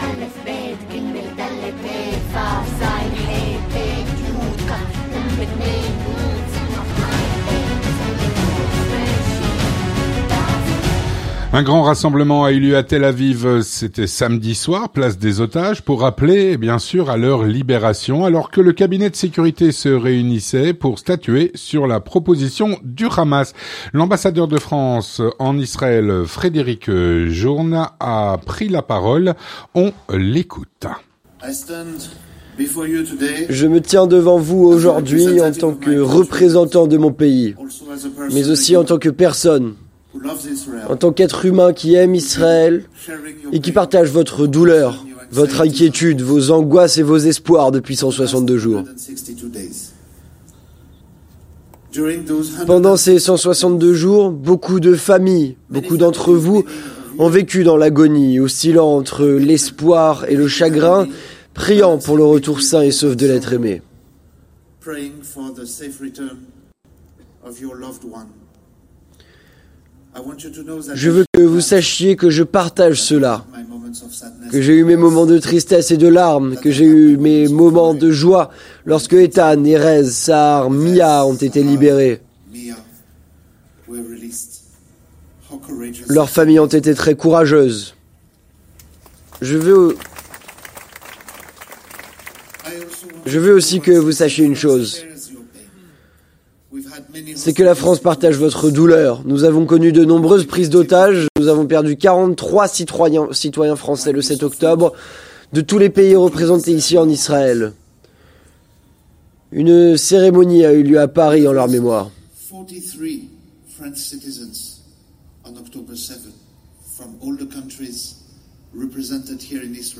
Un grand rassemblement a eu lieu à Tel-Aviv, samedi soir, place des otages, pour appeler à leur libération, alors que le cabinet de sécurité se réunissait pour statuer sur la proposition du Hamas. L’ambassadeur de France en Israël, Frédéric Journès y a pris la parole.
Un reportage